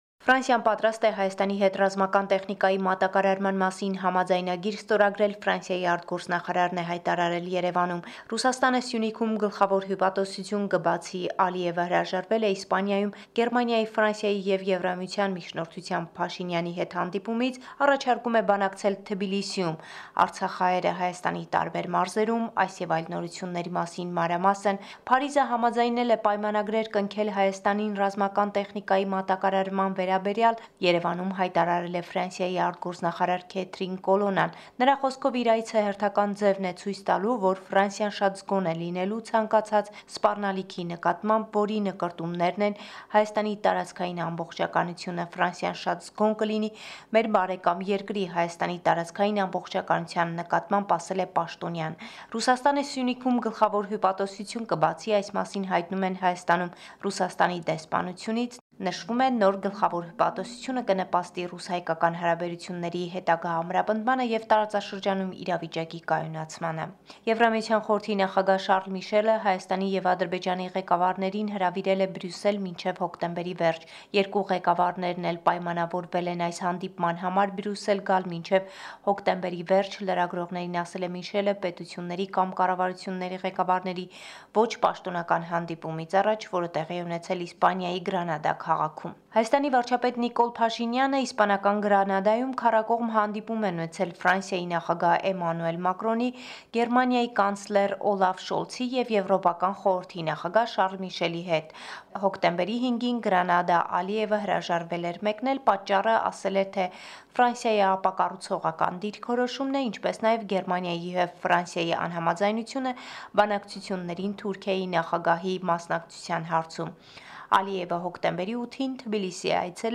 Latest News from Armenia – 10 October 2023